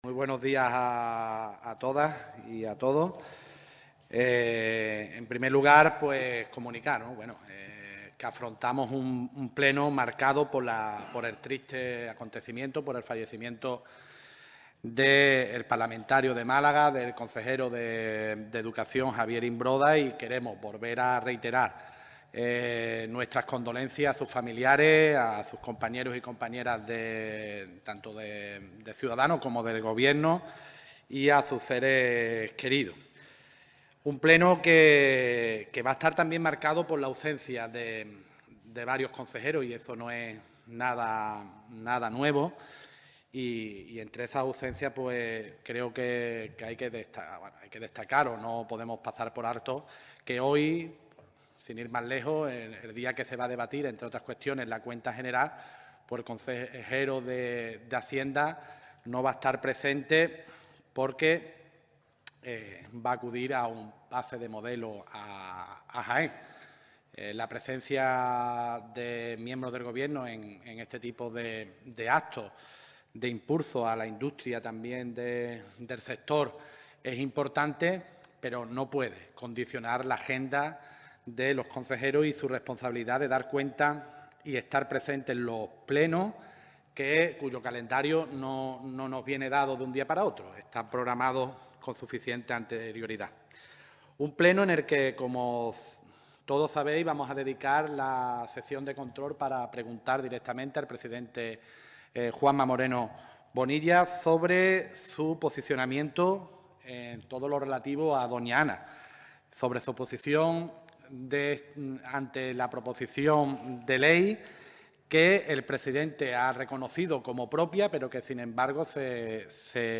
En rueda de prensa, Ahumada ha asegurado que esto supone «una privatización de la sanidad, y una concepción del derecho a la salud como un negocio».